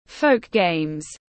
Folk games /fəʊk geɪmz/